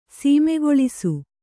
♪ sīmegoḷisu